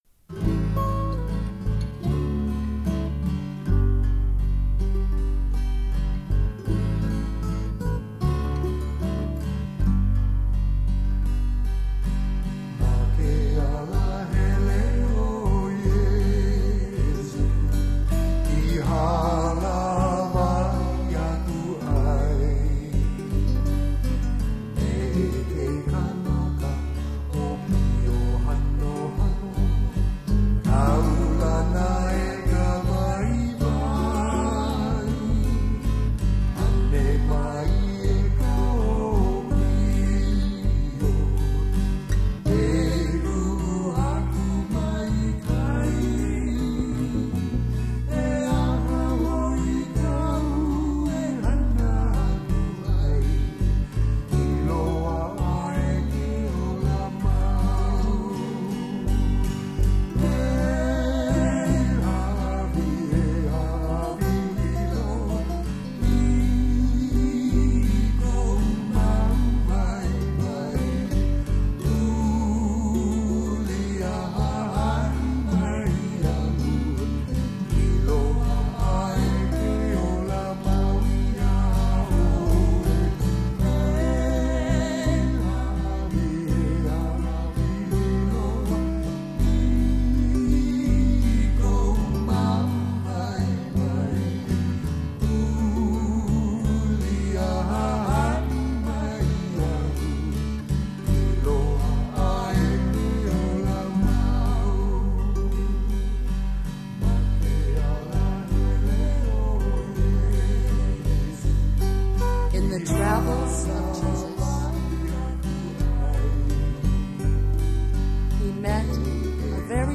1. Devotional Songs
Major (Shankarabharanam / Bilawal)
8 Beat / Keherwa / Adi
Lowest Note: g2 / E (lower octave)
Highest Note: D2 / A